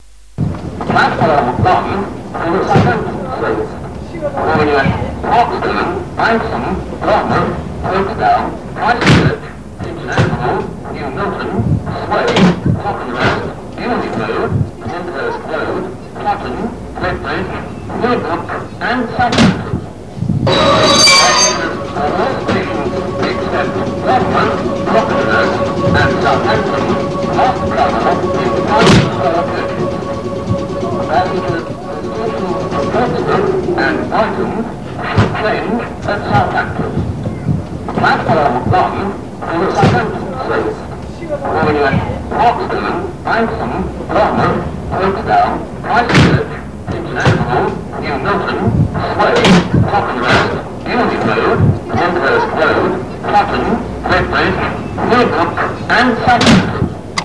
Poole pre-recorded platform announcement number 159 (year: 1989)